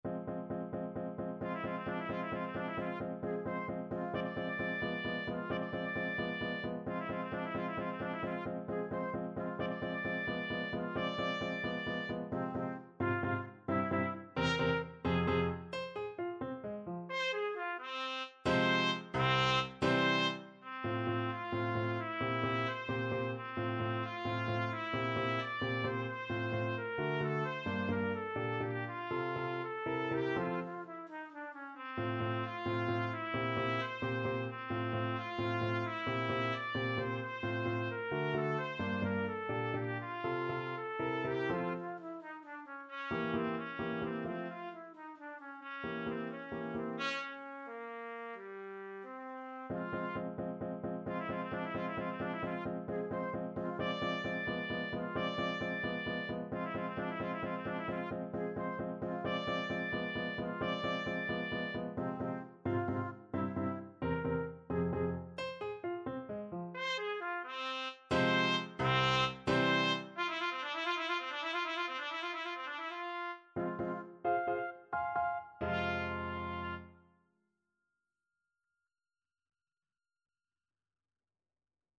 Ballade from 25 Progressive Pieces Trumpet version
F minor (Sounding Pitch) G minor (Trumpet in Bb) (View more F minor Music for Trumpet )
3/8 (View more 3/8 Music)
Allegro con brio (.=104) .=88 (View more music marked Allegro)
Classical (View more Classical Trumpet Music)